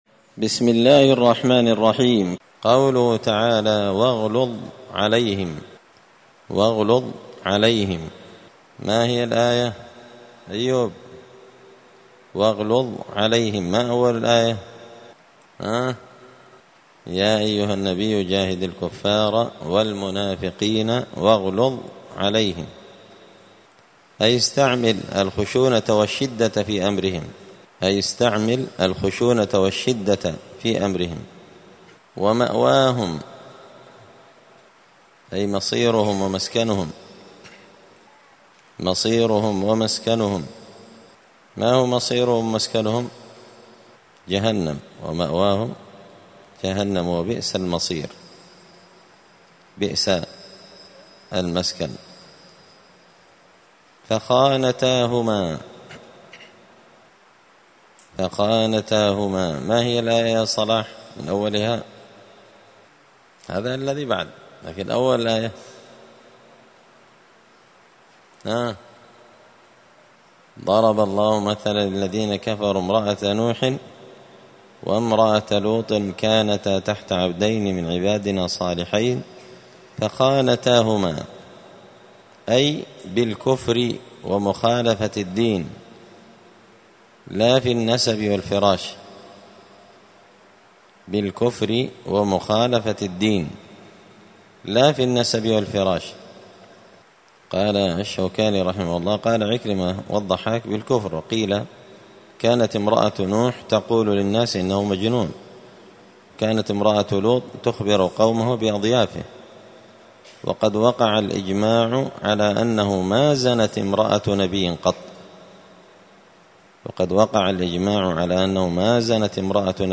*(جزء المجادلة سورة التحريم الدرس 156)*